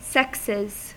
Ääntäminen
Ääntäminen US : IPA : [ˈsɛk.sɪz] Haettu sana löytyi näillä lähdekielillä: englanti Käännöksiä ei löytynyt valitulle kohdekielelle.